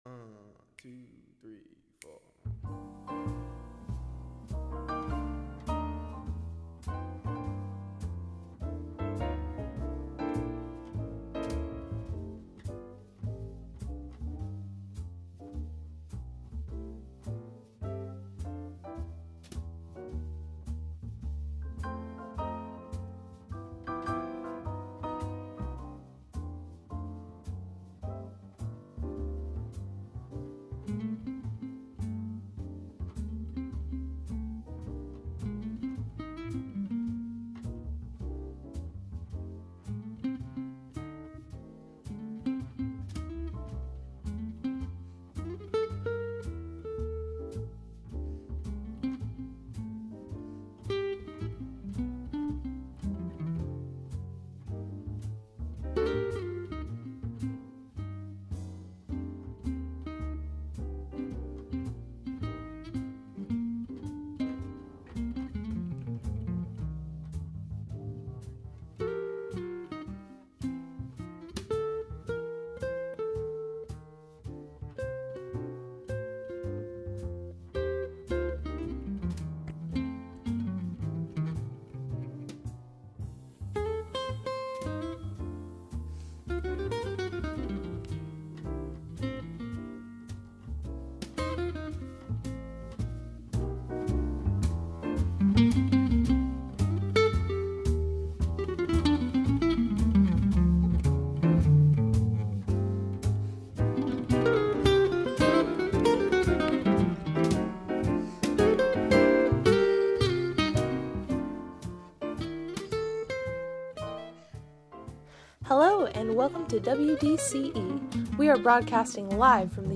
cool jazz set